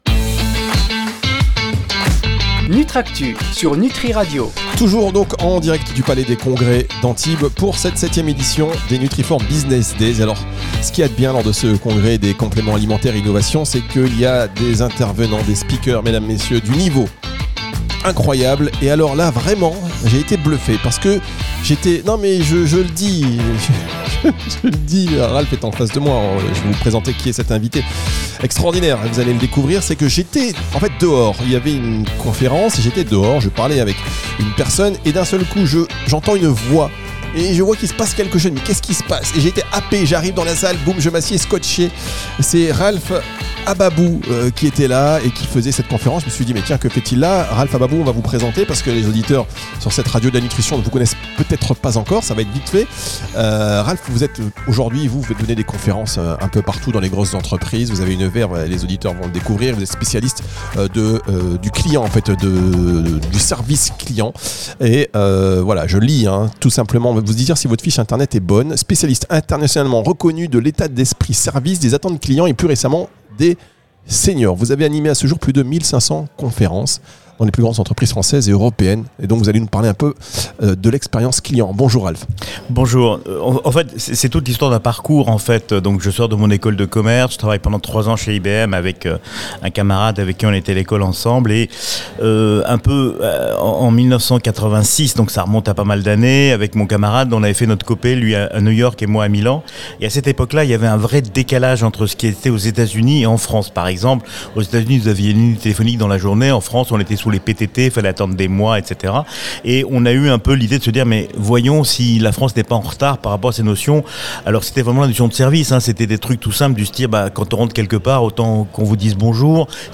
lors de la 7em édition des NFBD